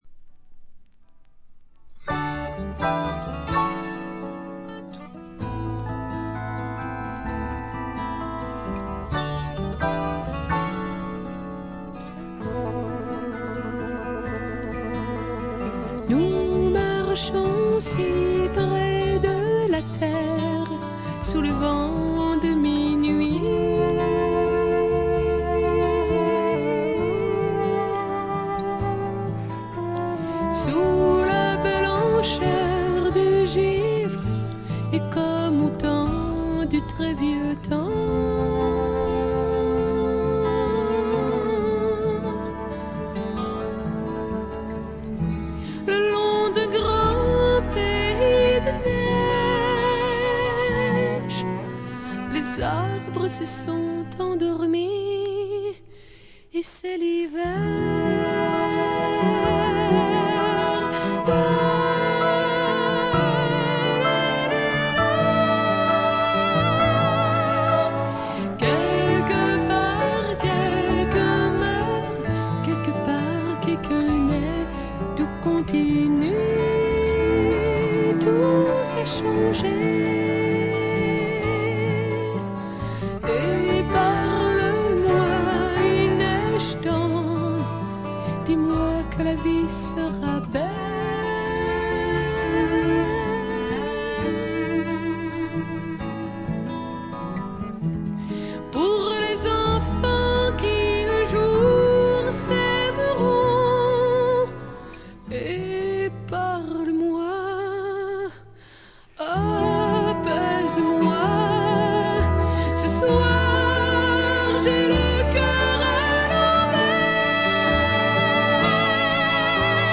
静音部で軽微なバックグラウンドノイズやチリプチが少々認められる程度。
カナディアン・プログレッシヴ・ドリーミー・フォークの名作。
試聴曲は現品からの取り込み音源です。